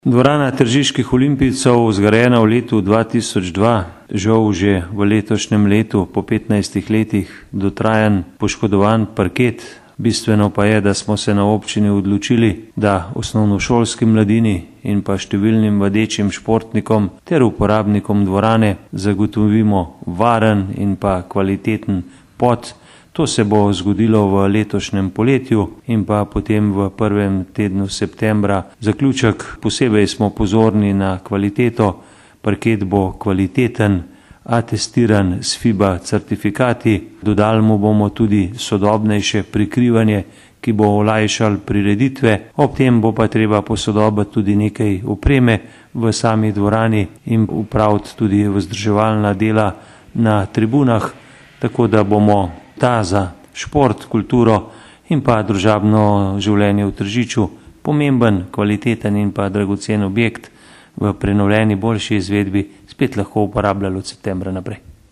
89089_izjavazupanobcinetrzicmag.borutsajovicoprenovidto.mp3